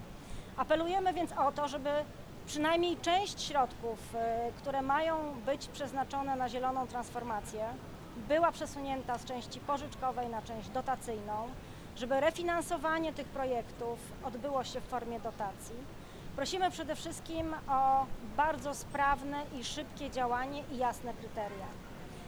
Powinny być one dostępne dla samorządów jako dotacje, a nie jako pożyczki- mówiła Magdalena Czarzyńska – Jachim, prezydentka Sopotu.